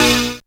37he01syn-c#.wav